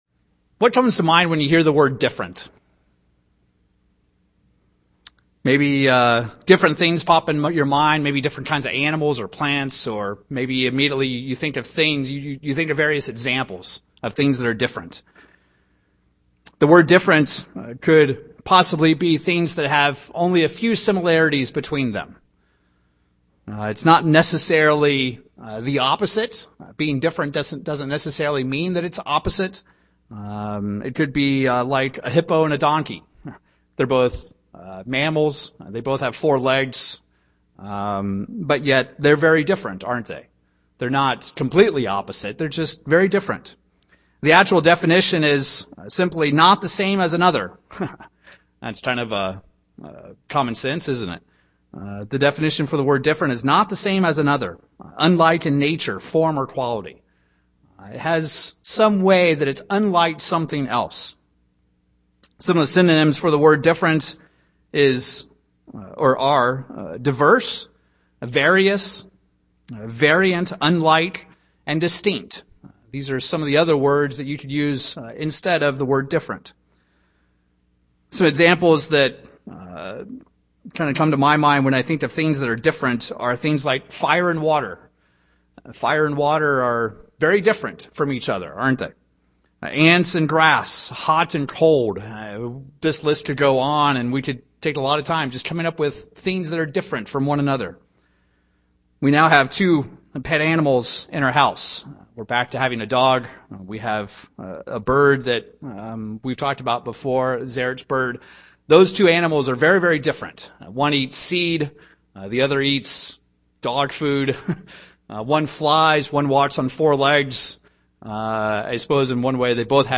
In this sermon we'll discuss working through those differences in order to have a happy Godly marriage.
Given in Wichita, KS